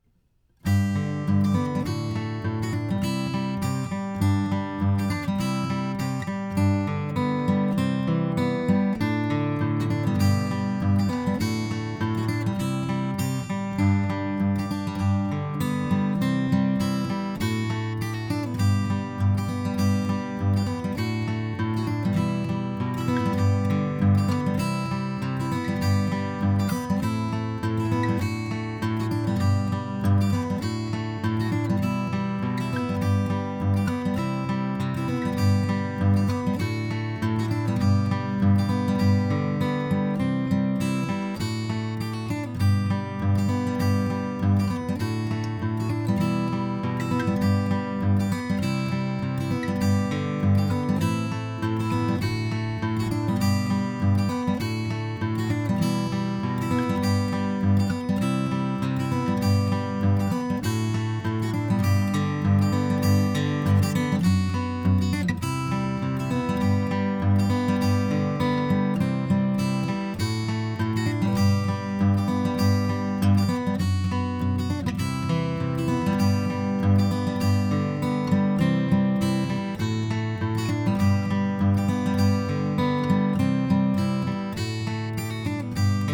acousticguitar_SSL4KEQ-A.flac